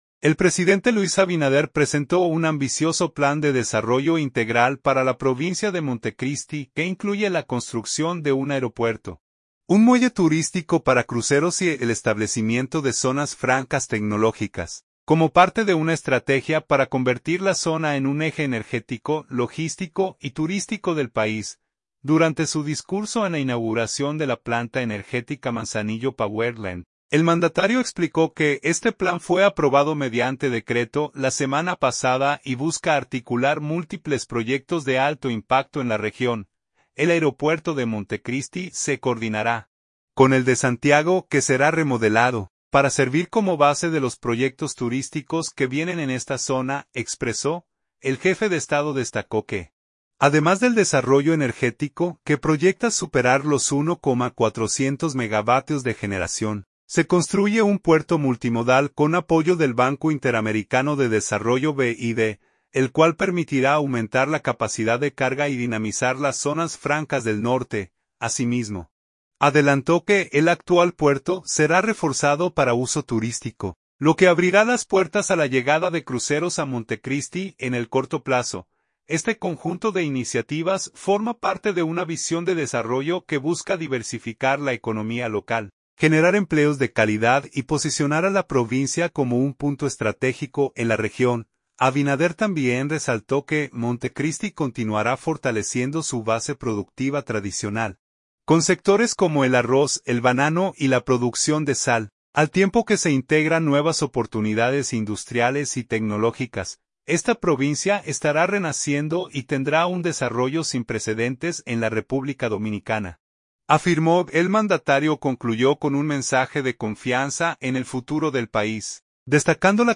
Durante su discurso en la inauguración de la planta energética Manzanillo Power Land, el mandatario explicó que este plan fue aprobado mediante decreto la semana pasada y busca articular múltiples proyectos de alto impacto en la región.